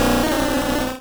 Cri de Lamantine dans Pokémon Rouge et Bleu.